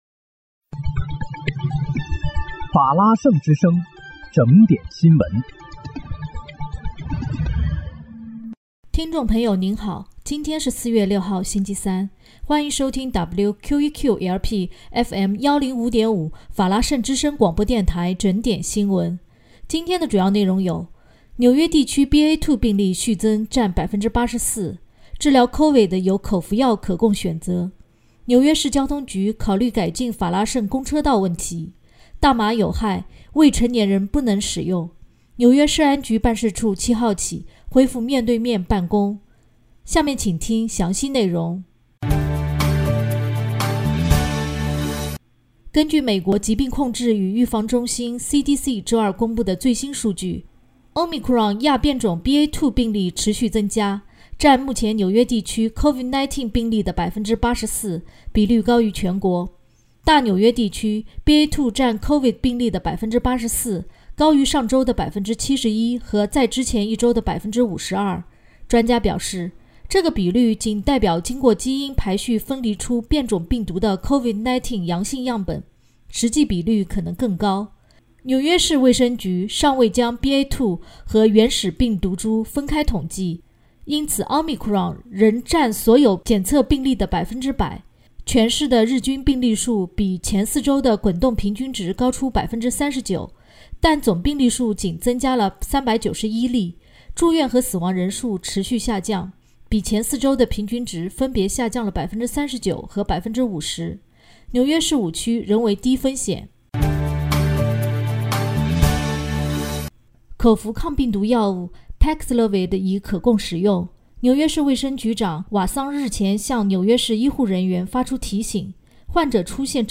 4月6日（星期三）纽约整点新闻